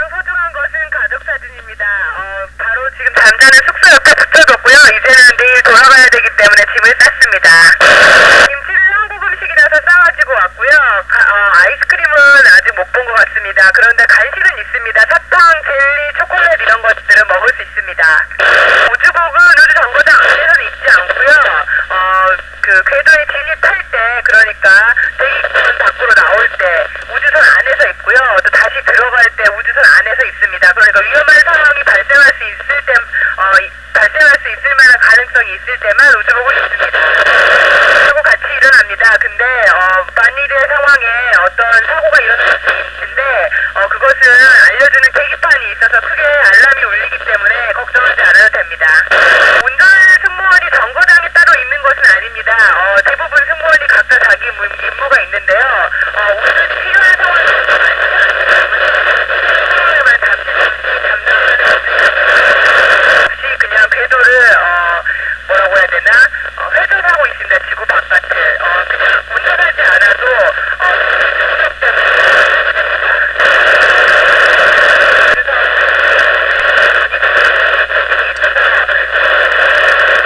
우주인 이소연 2차교신 내용